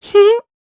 scared-step-old1.wav